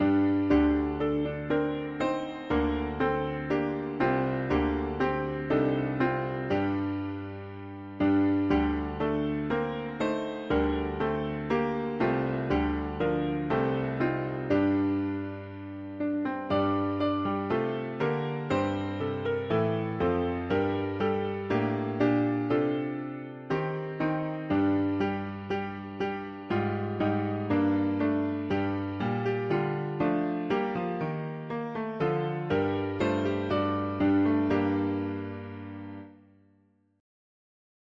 French folk melody
Key: G minor